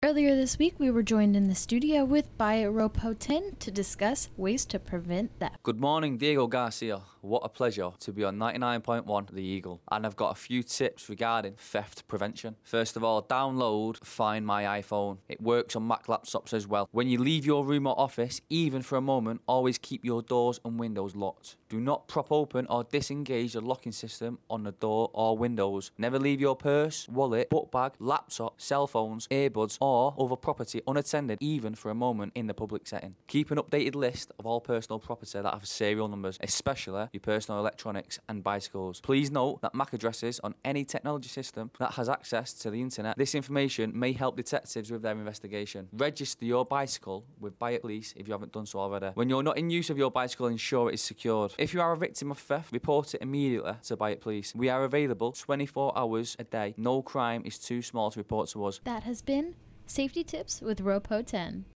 Theft PreventionNSFDiegoGarciaRadioSpotROPOHacks